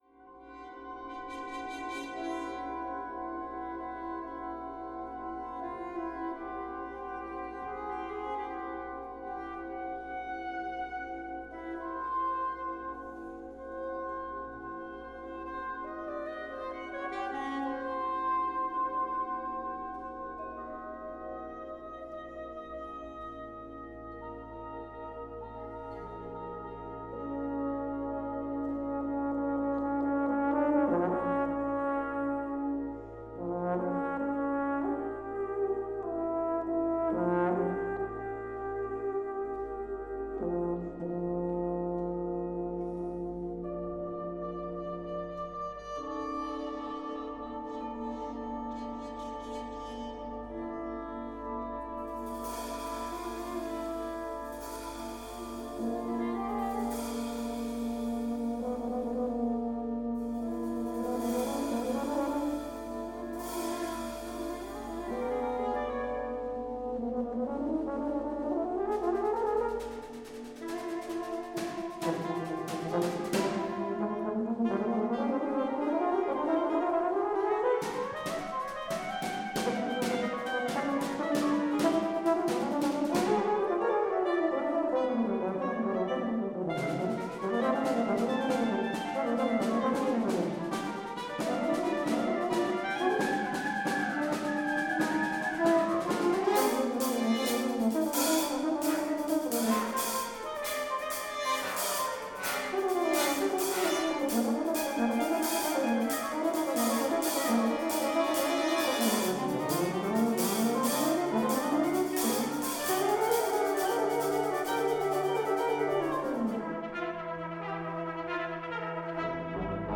Sopraansax en Euphonium passage